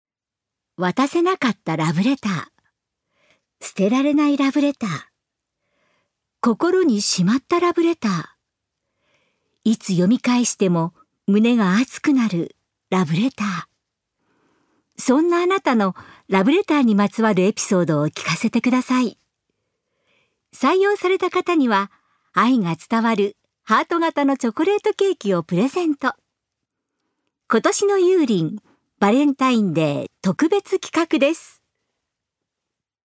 ナレーション３